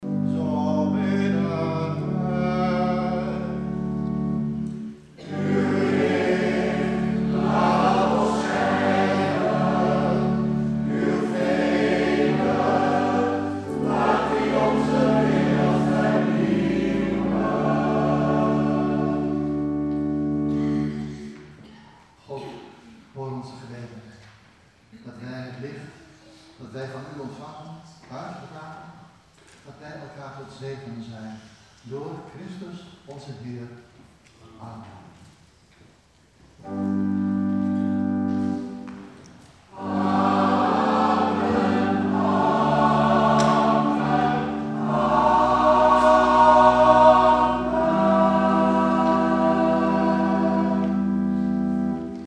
Maria Lichtmis  - zondag 5 februari 2017
viering met kinderkoor en Nicolaaskoor
De voorbeden,  acclamatie: